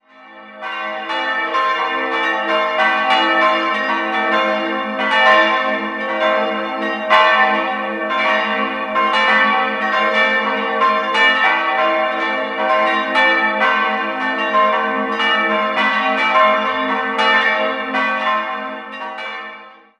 Willibaldglocke g'-4 ca. 900 kg ca. 1.130 mm 1688 Ursus Laubscher, Ingolstadt Marienglocke a'+1 ca. 600 kg 983 mm 1698 Johann Ulrich Schelchshorn, Neuburg Konrad-von-Parzham-Glocke c''+3 304 kg 761 mm 1977 Glockengießerei Heidelberg Kleine Glocke b''+3 ca. 100 kg ca. 506 mm 14. Jh. unbezeichnet Quelle: Glockenkartei der Diözese Eichstätt